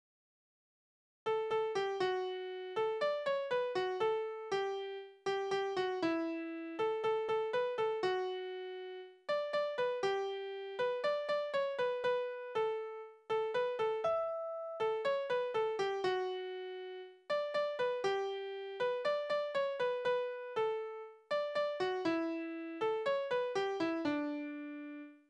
Tonart: D-Dur
Taktart: 4/4
Tonumfang: große None
Besetzung: vokal
Anmerkung: Vortragsbezeichnung: Ruhig